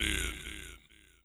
GAR FX A.wav